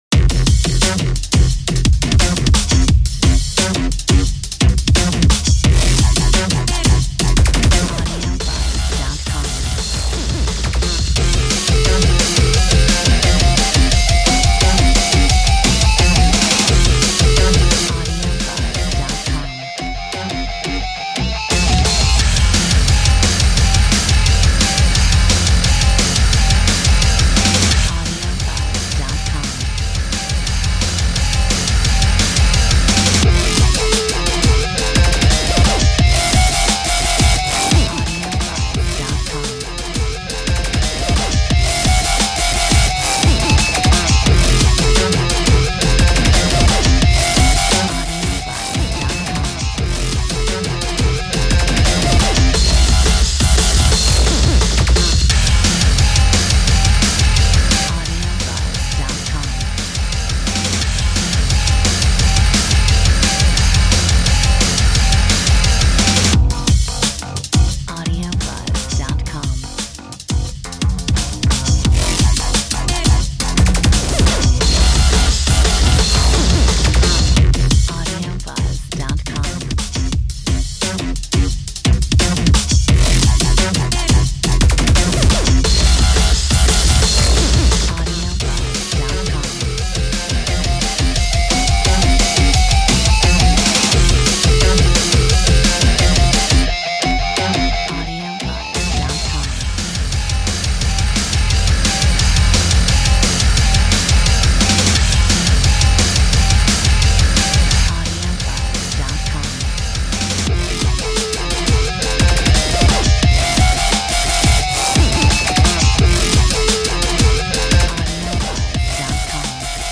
Metronome 174